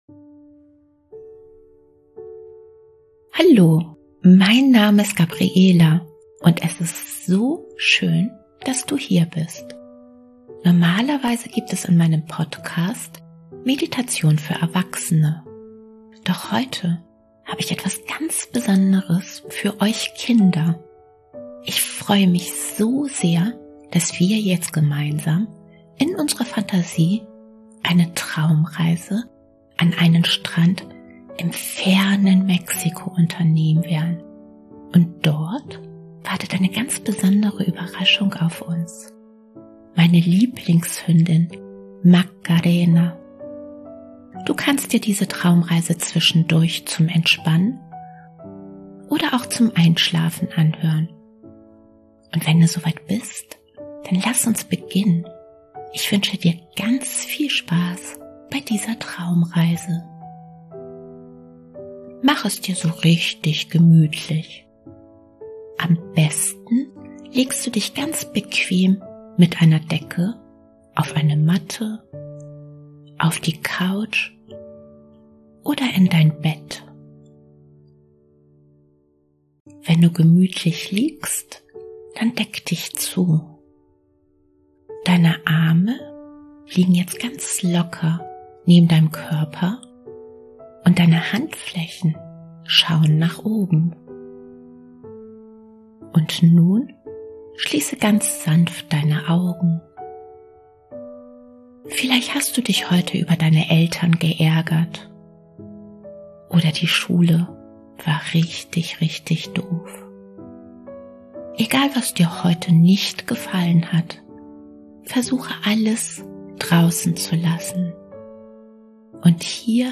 Doch heute habe ich etwas ganz Besonderes für euch Kinder: eine entspannende Traumreise für Kinder am Strand. Gemeinsam werden wir in unserer Fantasie an einen wunderschönen Strand im weit entfernten Mexiko reisen.